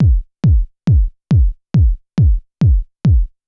BD        -R.wav